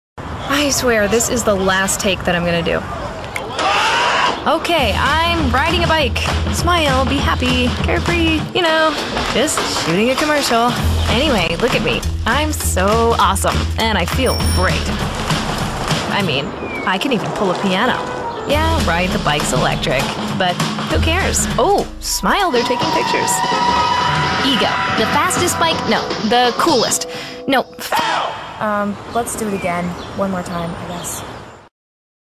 Female Voice Over, Dan Wachs Talent Agency.
Current, Modern, Young Mom, Heartfelt.
Conversational